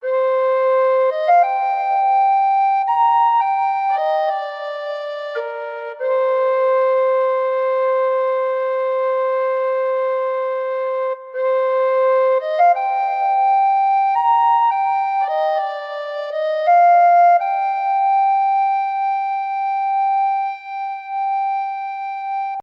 Genre: Folk
Tags: tin whistle , recorder , Irish folk , Celtic , melody